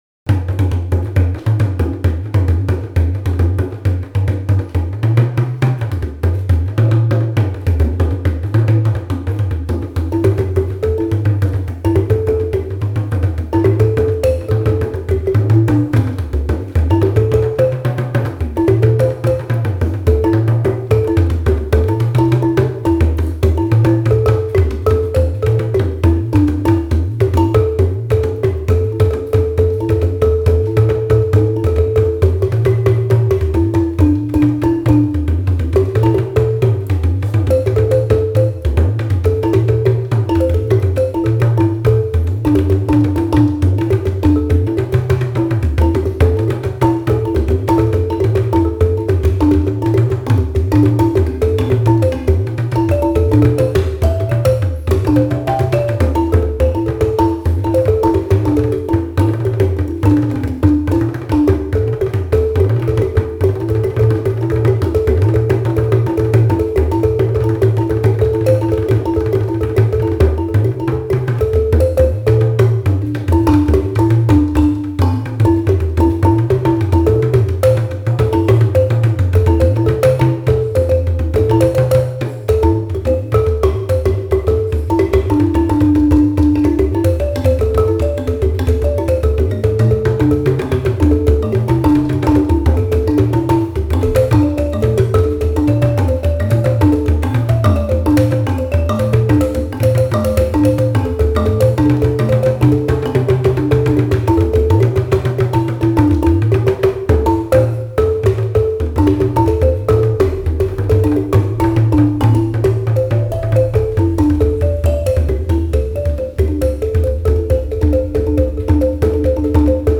kalimba
sax, bass and balafon.